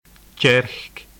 Dearc (Scottish Gaelic pronunciation: [tʲɛrxk]